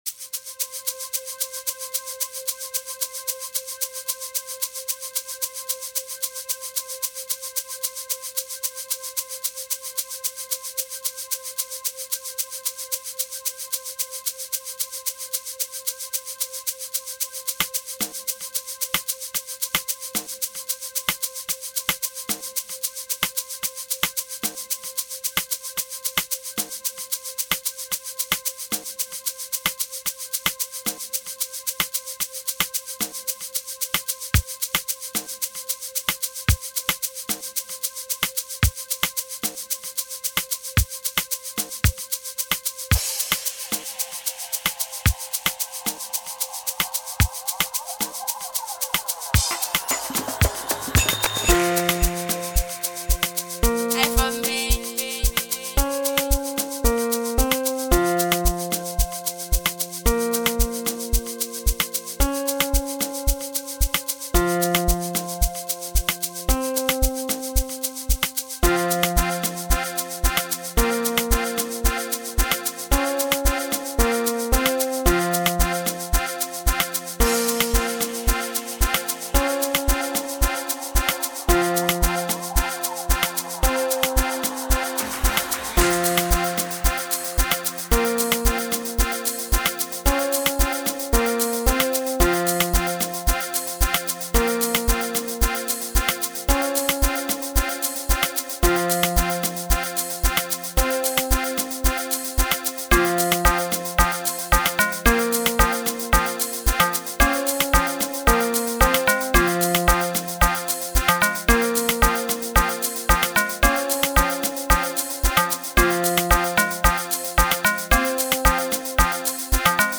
05:44 Genre : Amapiano Size